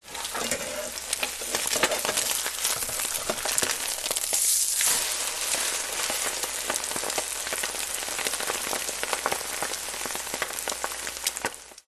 На этой странице собраны натуральные звуки приготовления яичницы: от разбивания скорлупы до аппетитного шипения на сковороде.
Шорох переворачиваемой яичницы на сковороде